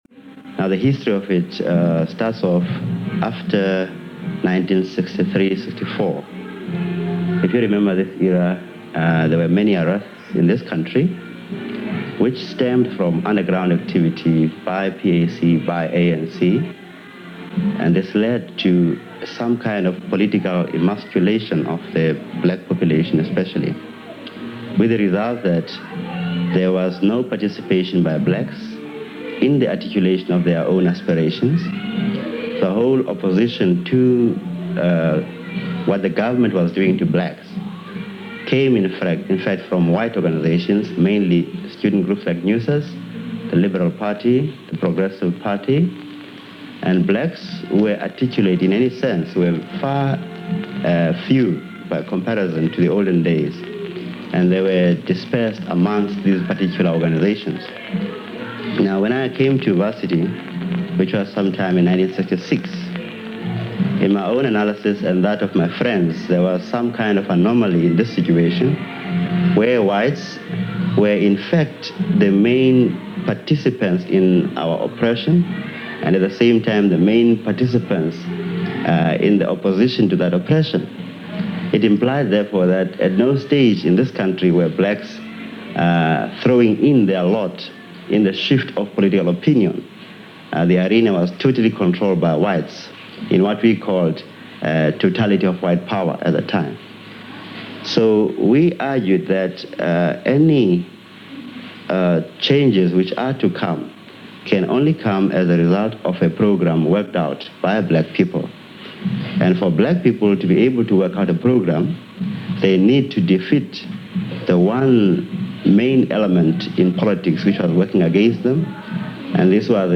This interview, done within a day of his arrest by police, was conducted under less than ideal circumstances. Music plays in the background as a way of preventing eavesdropping by police bugging devices. He explains the history of the Anti-Apartheid Movement and his role in attempting to bring together groups, both black and white, to change the oppressive polices of the Pretoria government.
stephen-biko-interview-1977.mp3